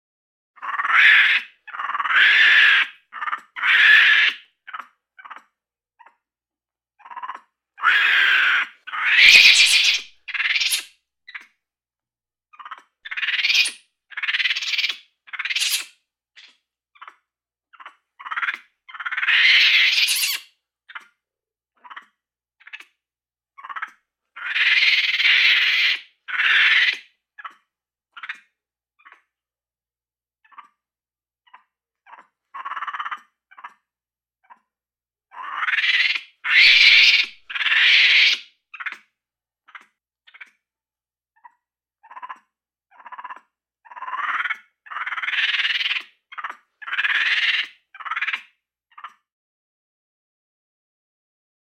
Monkey, Capuchin Chatter, Screams. Loud, High Pitched Scream With Chatter Sound. Close Perspective.